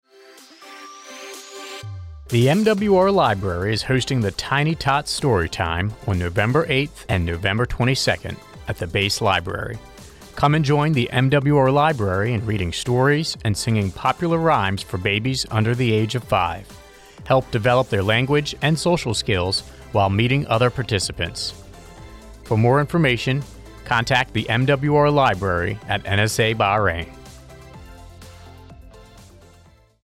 Thirty-second commercial spot highlighting MWR Bahrain's Tiny Tots Storytime event, to be aired on AFN Bahrain’s morning and afternoon radio show.